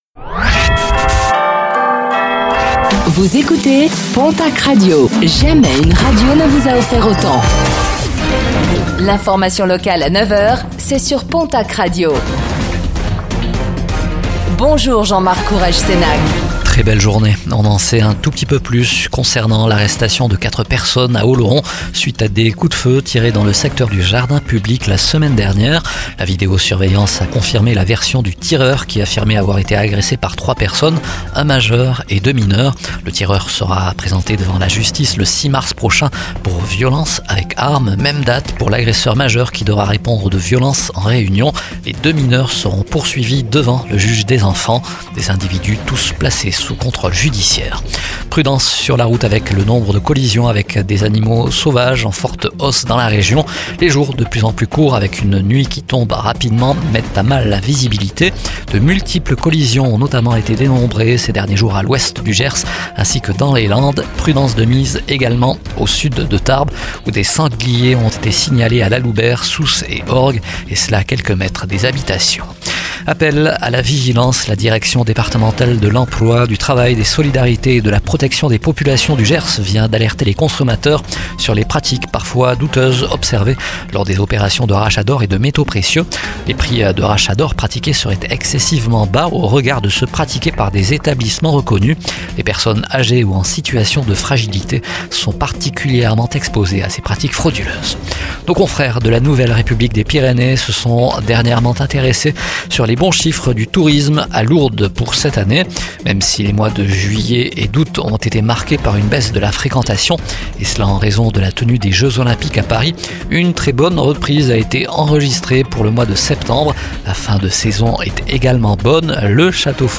Infos | Mardi 03 décembre 2024